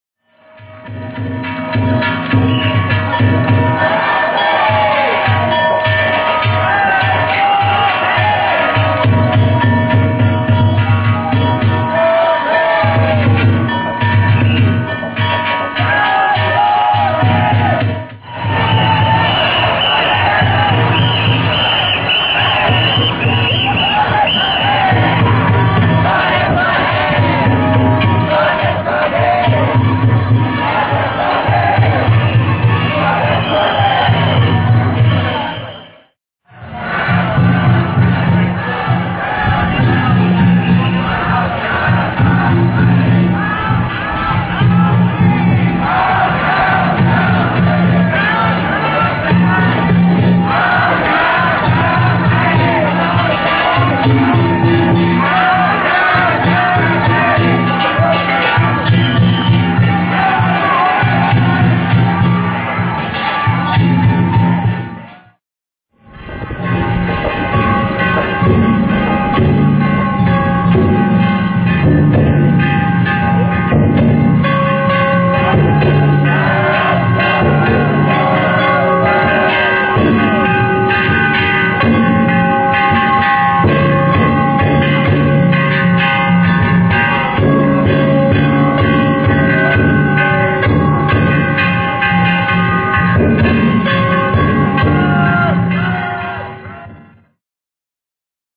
平成２９年５月５日、東灘区の住吉地区地車連合曳き：住吉駅北を見に行ってきました。